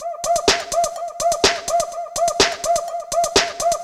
Index of /musicradar/retro-house-samples/Drum Loops
Beat 12 No Kick (125BPM).wav